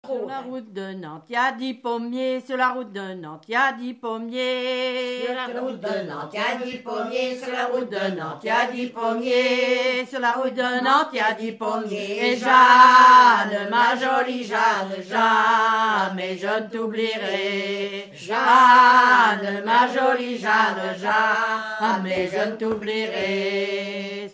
Fonction d'après l'analyste gestuel : à marcher
Genre énumérative
Catégorie Pièce musicale inédite